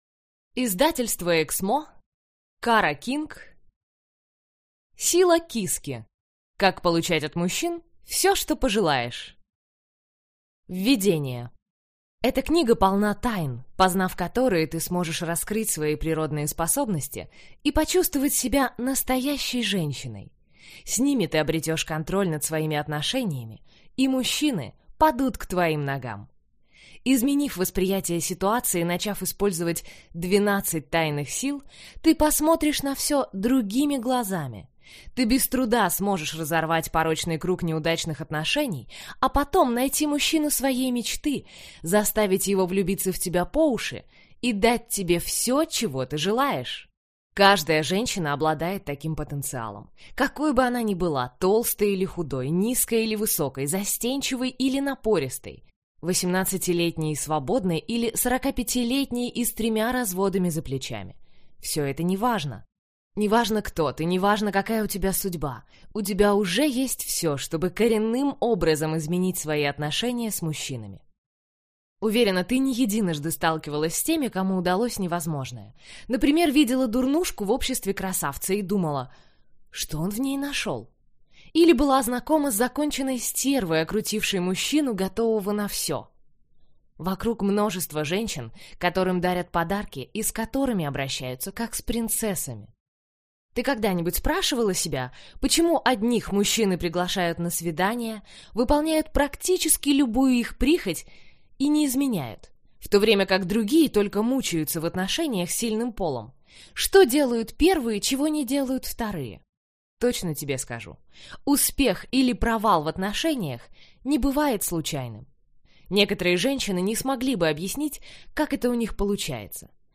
Аудиокнига Сила киски. Как получать от мужчин все, что пожелаешь | Библиотека аудиокниг